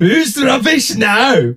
ash_lead_vo_02.ogg